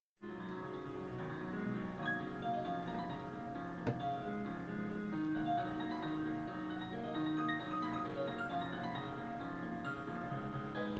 Also, the quality is pretty poor, because I recorded it with my smartphone, to show it some friends.
Here is some overfitted but quite interesting Amelié music: